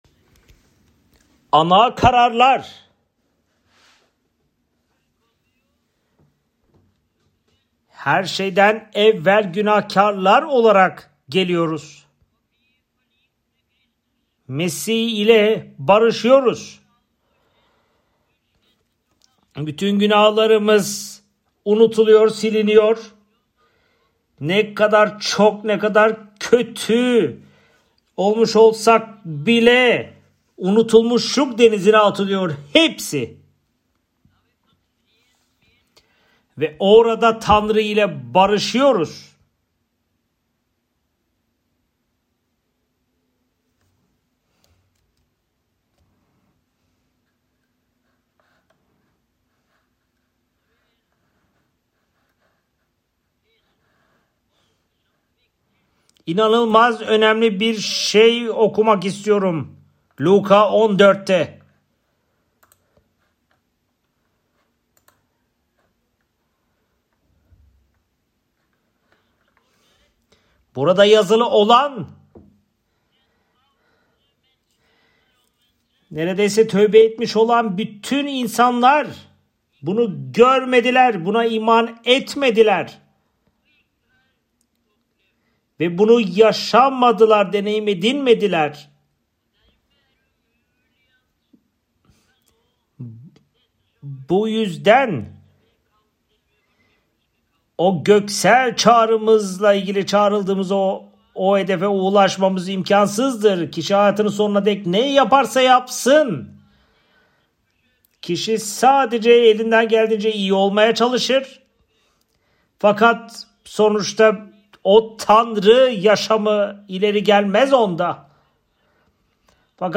audio/Tema%202/Tr/1969-01.mp3 Click to listen to the speech.